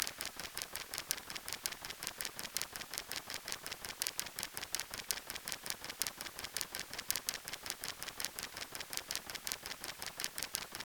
Klickgeräusche durch bewegliche Magnete bei Cochlea Implantaten: Fallbeschreibung und akustische Messungen | Publisso
Klickgeräusch Explant (Attachment2_zaud000013.wav, audio/x-wav, 1.84 MBytes)